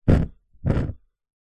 Металлическая зажигалка Zippo с двойным тушением пламени